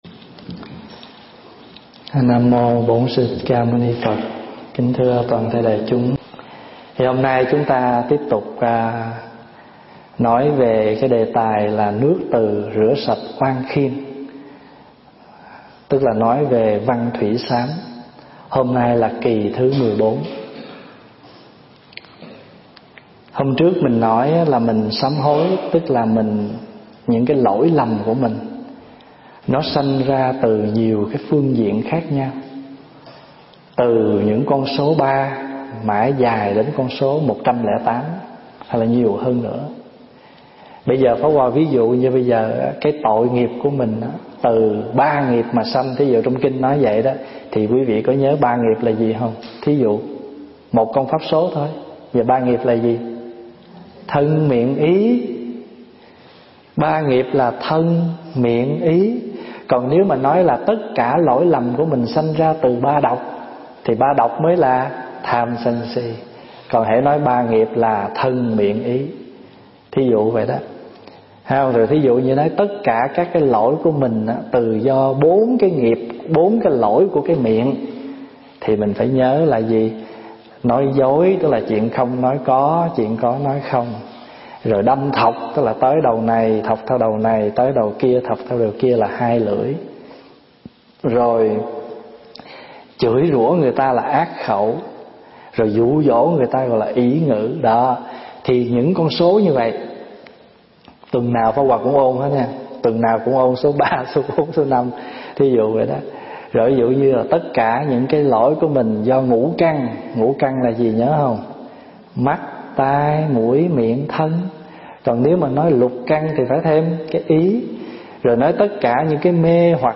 thuyết giảng tại Tu Viện Trúc Lâm, Canada, ngày 26 tháng 5 năm 2013